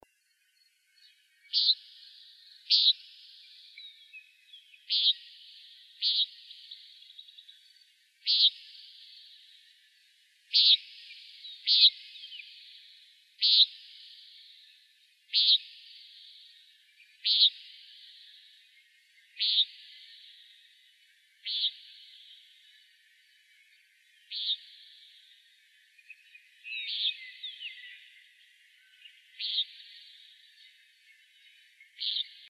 commonnighthawk.wav